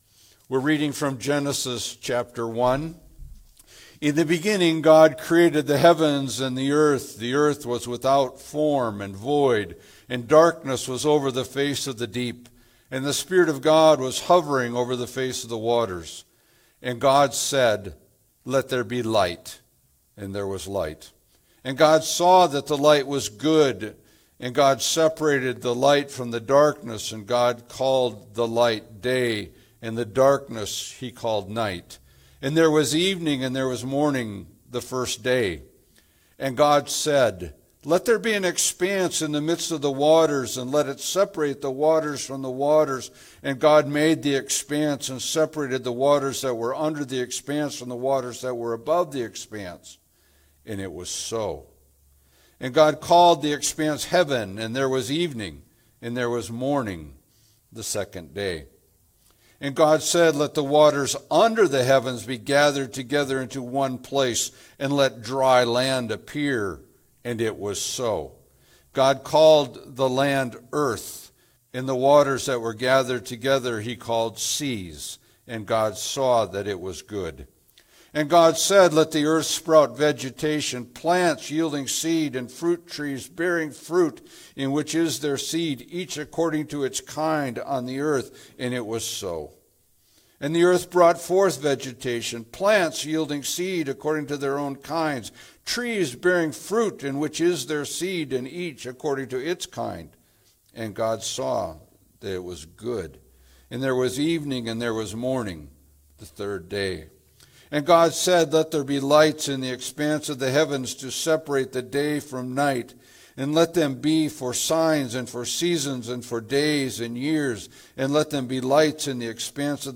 Passage: Genesis 1-2:3 Service Type: Sunday Service